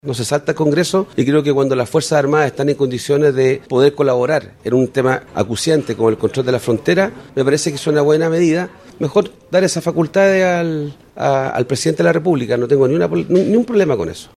En tanto, el senador del PPD, Ricardo Lagos Weber, restó dramatismo a la reforma constitucional y dijo que vendrá a facilitar el despliegue y trabajo de los militares en la zona.